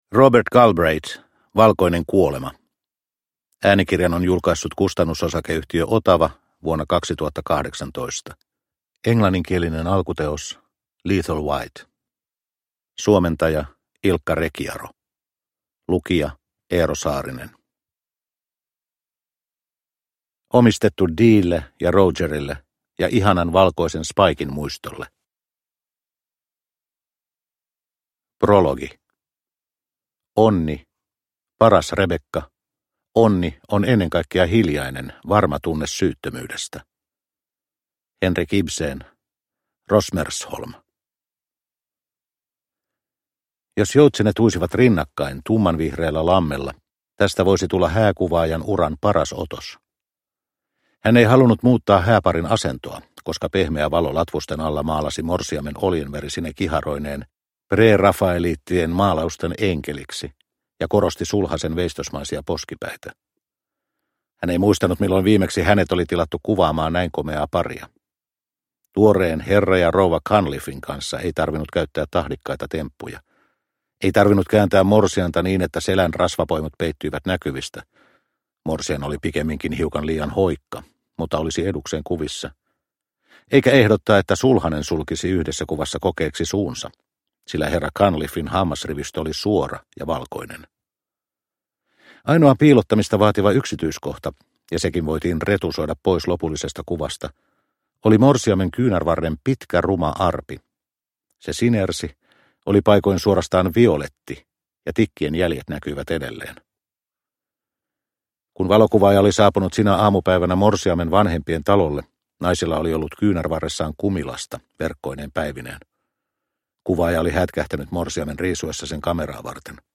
Valkoinen kuolema – Ljudbok – Laddas ner